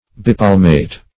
Search Result for " bipalmate" : The Collaborative International Dictionary of English v.0.48: Bipalmate \Bi*pal"mate\, a. [Pref. bi- + palmate.]